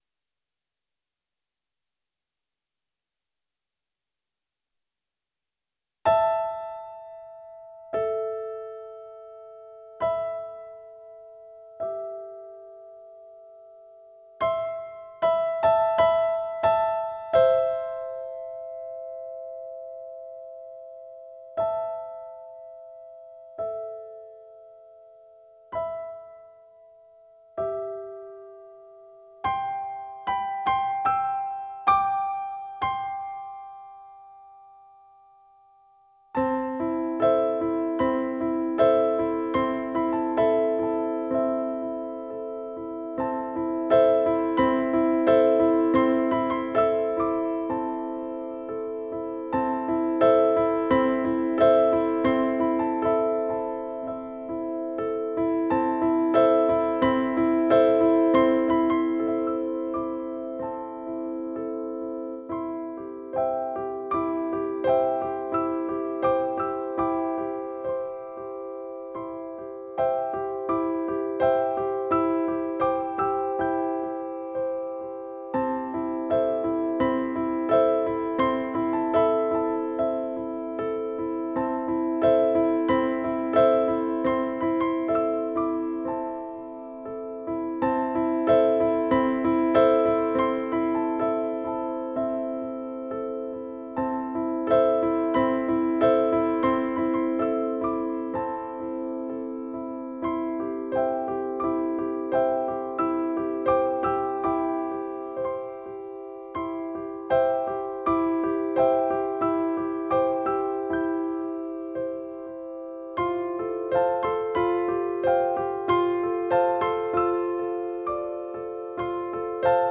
proyecto individual y minimalista